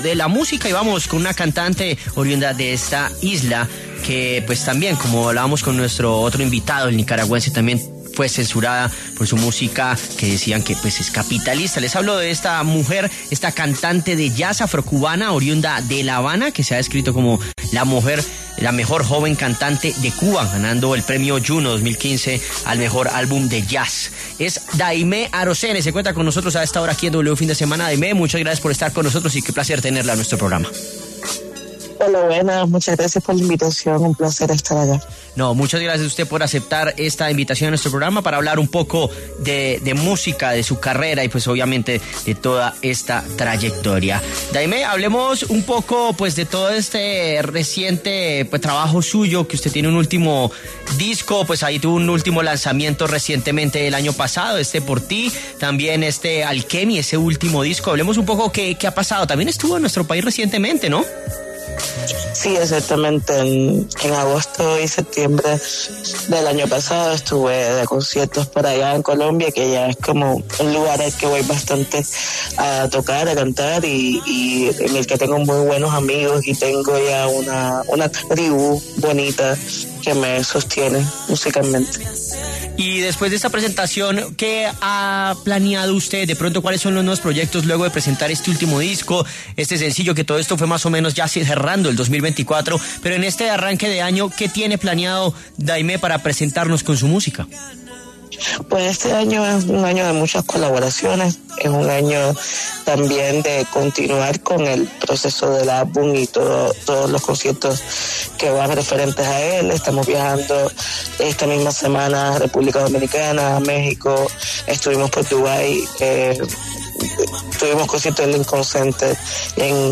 A los micrófonos de la W llegó la cantante de música afrocubana, Daymé Arocena, quien dejó la isla de Cuba tras ser censurada por sus canciones consideradas “capitalistas”.